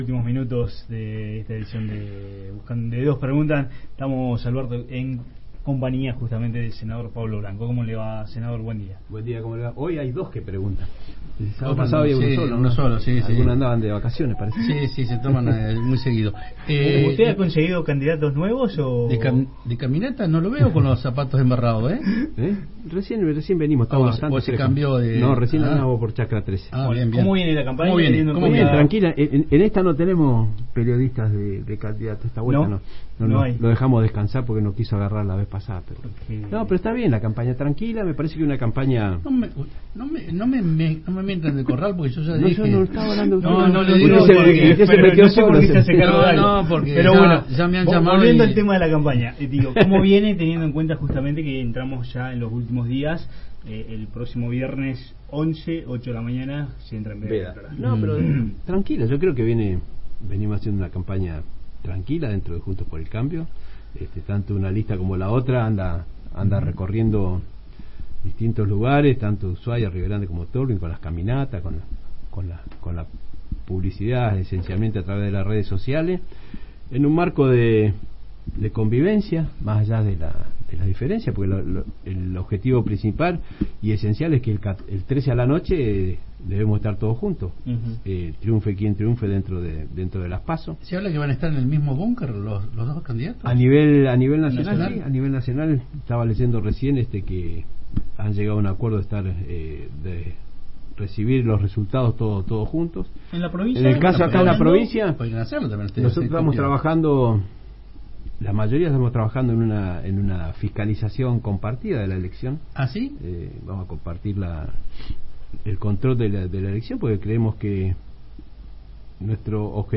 Río Grande.- El senador Pablo Blanco visitó los estudios de Radio Universidad 93.5 y Diario Provincia 23 en el último tramo para las PASO, y destacó que ha sido “una campaña tranquila”.